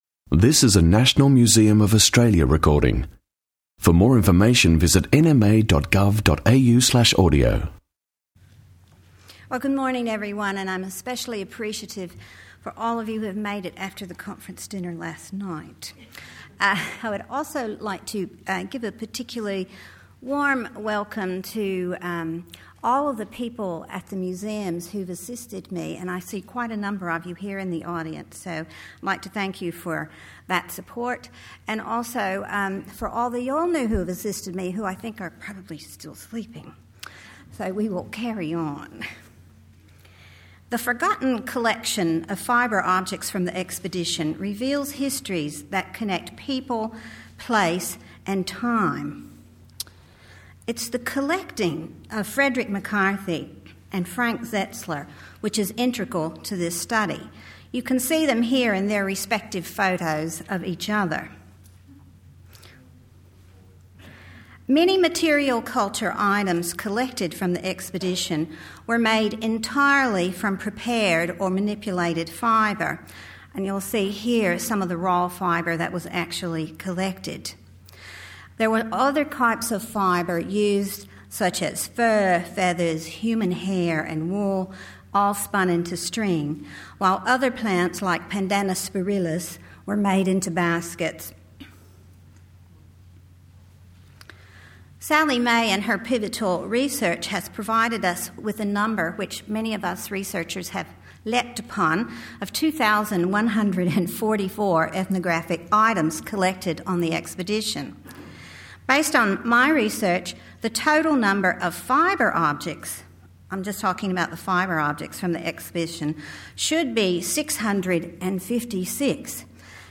Barks, Birds and Billabongs symposium 19 Nov 2009